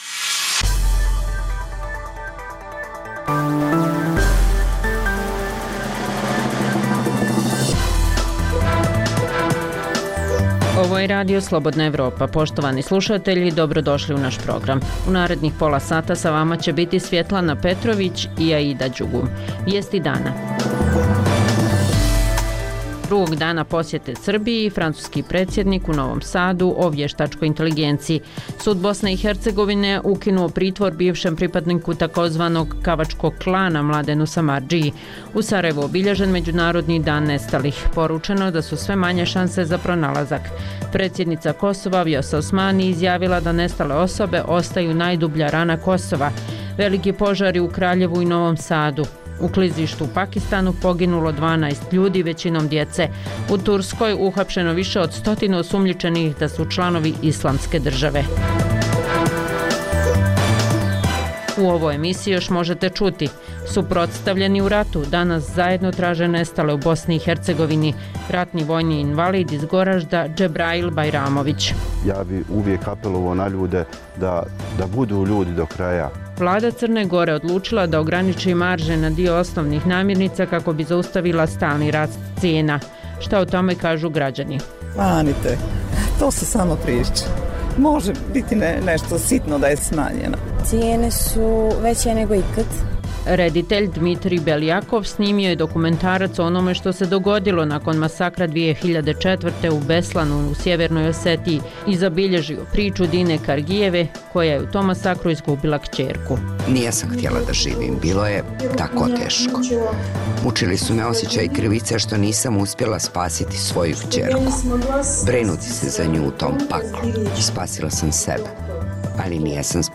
Dnevna informativna emisija Radija Slobodna Evropa o događajima u regionu i u svijetu.